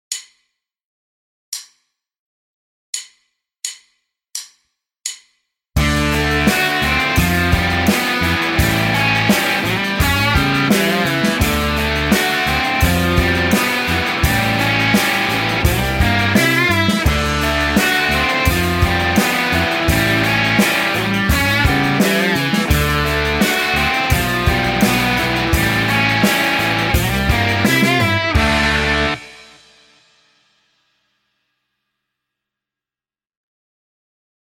1. Риффы на основе арпеджио
Чаще всего в брит-попе риффы строятся на арпеджио и переборах, с большим уклоном в мелодичность, а не на мощь звучания. Переборы можно дополнять другими приемами – хаммерами, пулами, бендами-подтяжками.